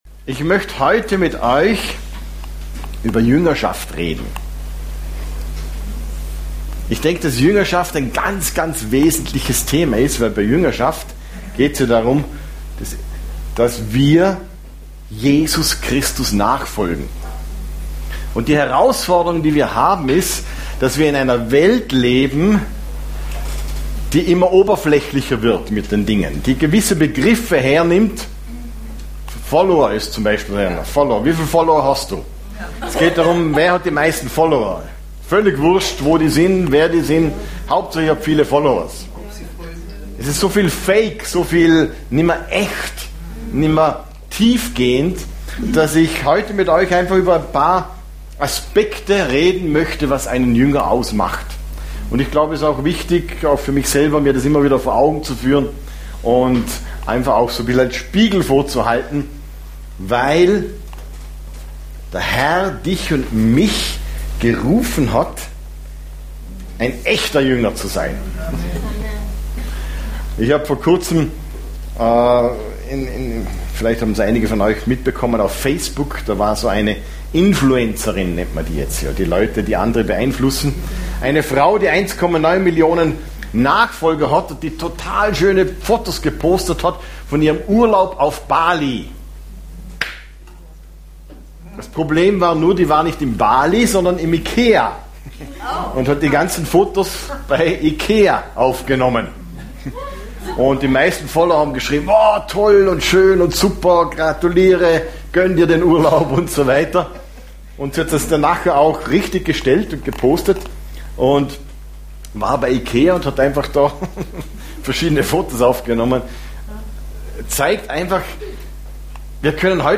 Sermons Archiv - Seite 11 von 12 - Freikirche Every Nation Innsbruck